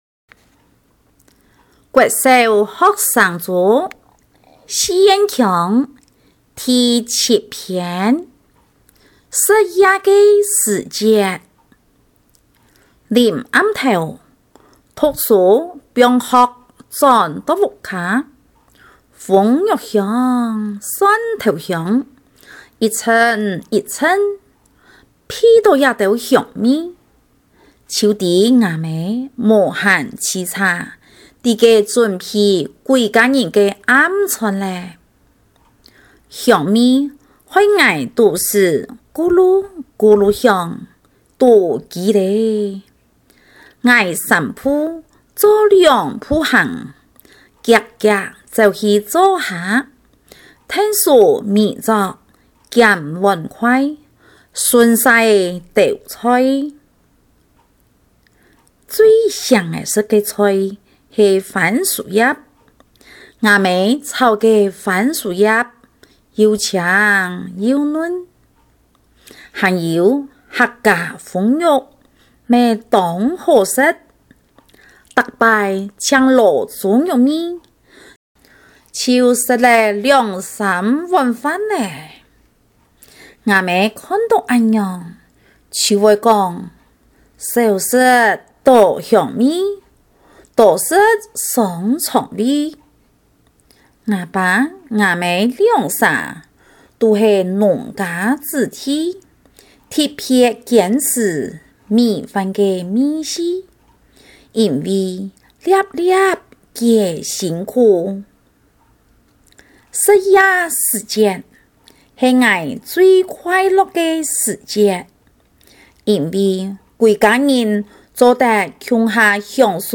校內多語文競賽---公告客語朗讀(四縣腔)---篇目2篇& 朗誦示範Demo帶